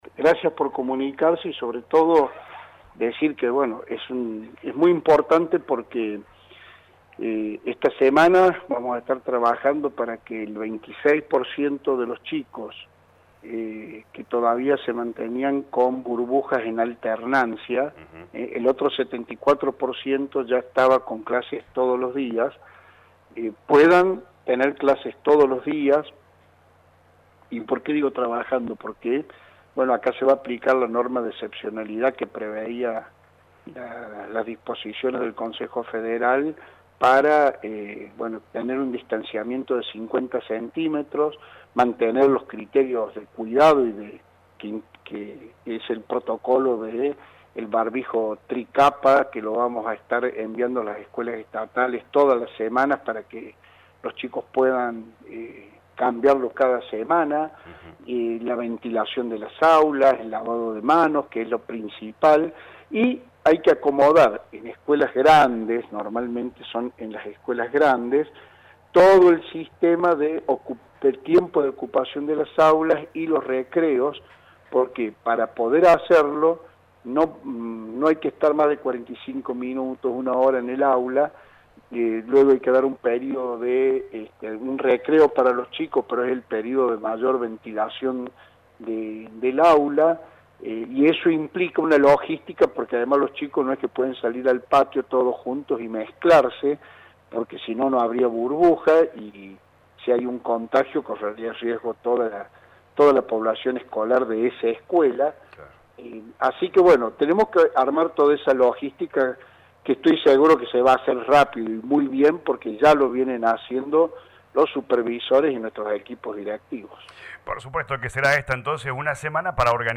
El Ministro de Educación de la provincia Walter Grahovac dialogó con LA RADIO 102.9 sobre la presencialidad plena en escuelas.